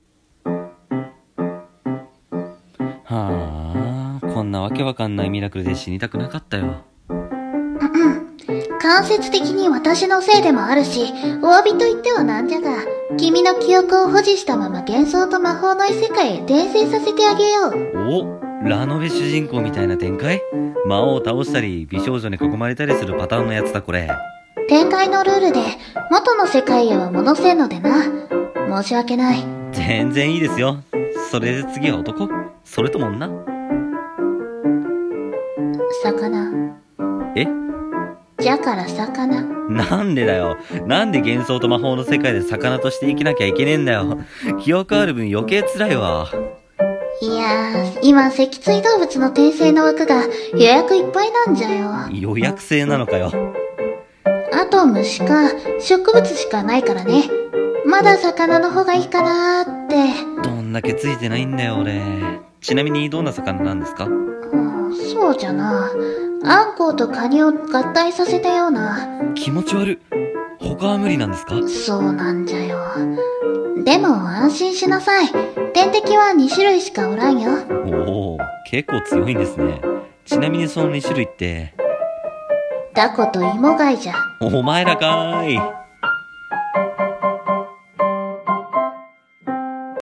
【ギャグ声劇】突然の死（後編）【掛け合い】